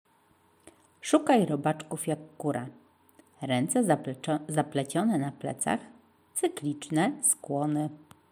kura
kura.mp3